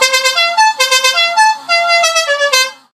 Clacson "La cucaracha